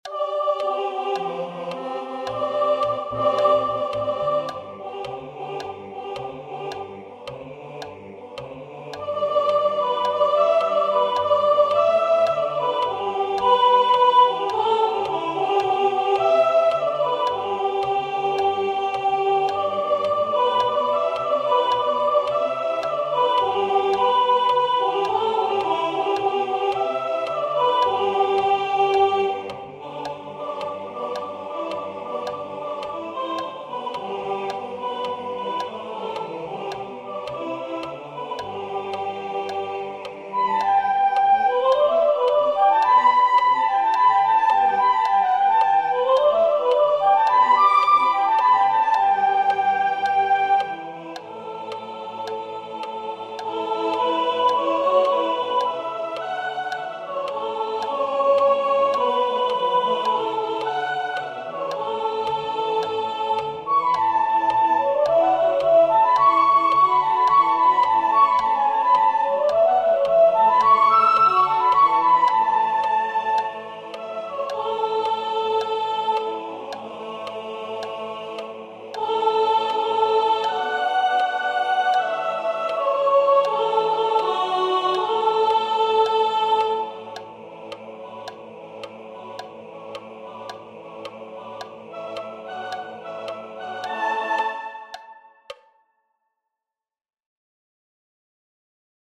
This page contains rehearsal files for choir members.
Sop1